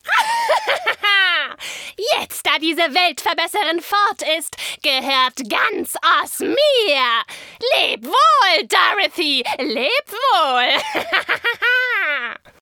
Böse Hexe Sprecher und Stimme ✓ | Sprachproben | Vita | Kontakt | Booking
Doku